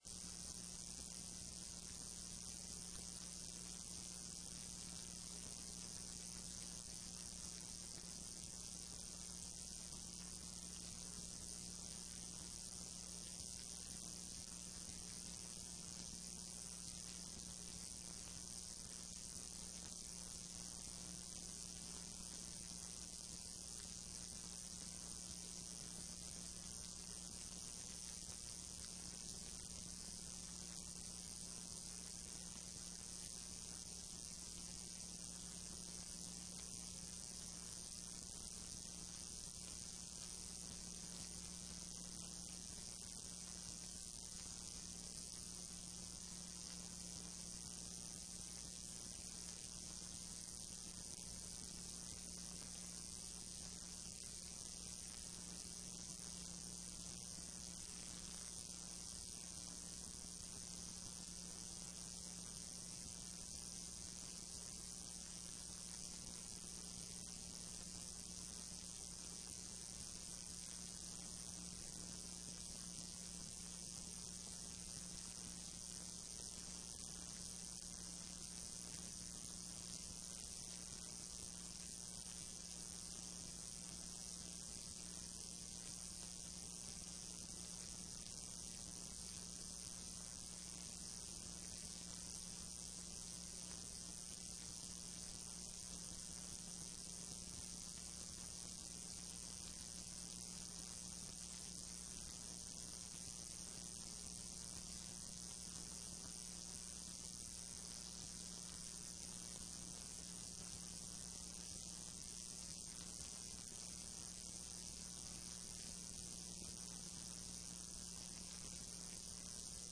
TRE-ES - Áudio da sessão 05.12.14